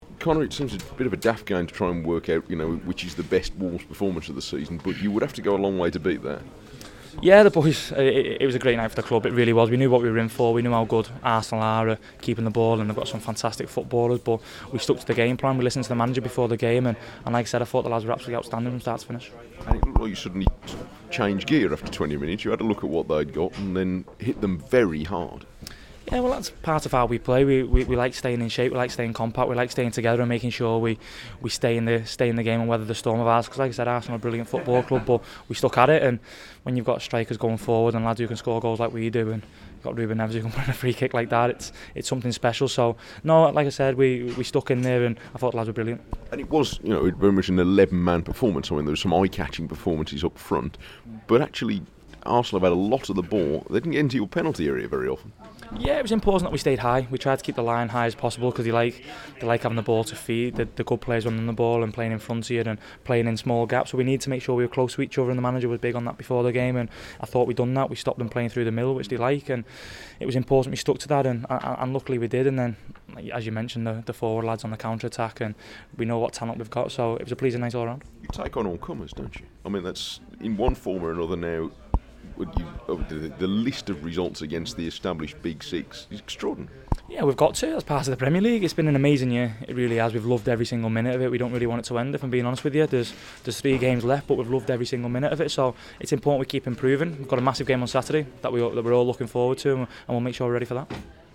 Conor Coady talks to BBC WM following Wolves 3-1 win over Arsenal